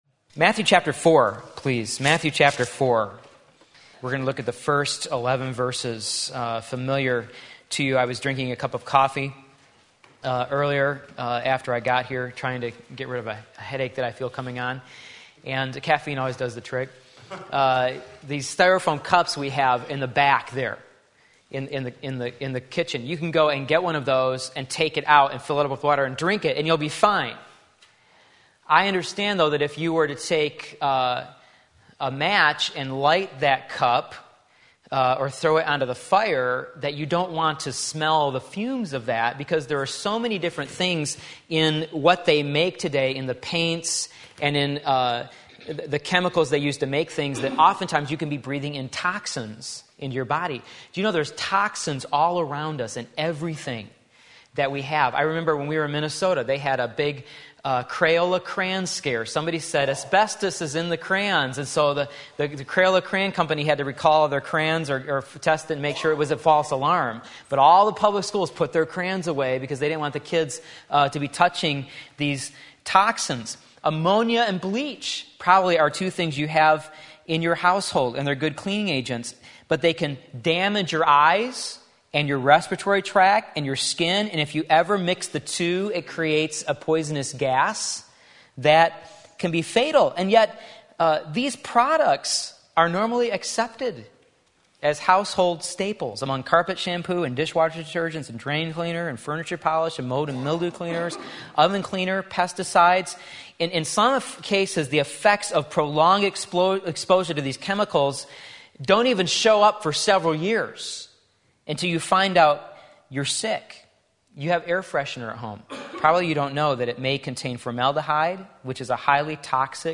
Sermon Link
Facing Temptation like Christ Matthew 4:1-11 Sunday Afternoon Service